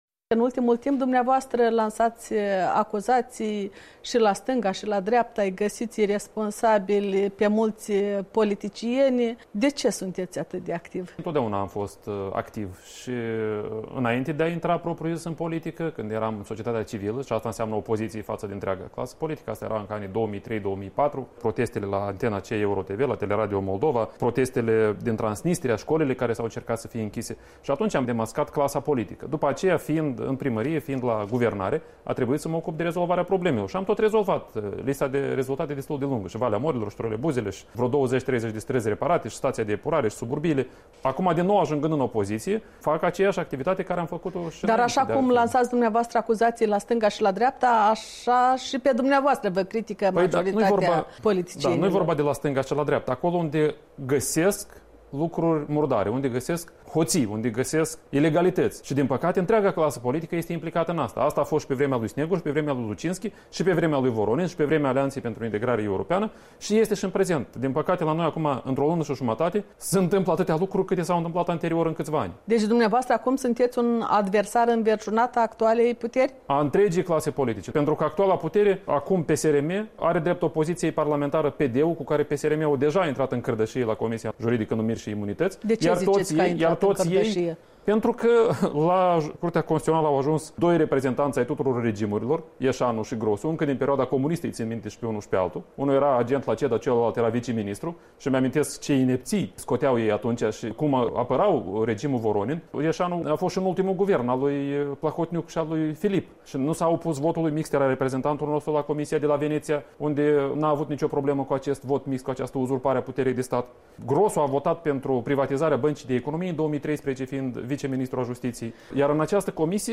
Un interviu cu liderul PL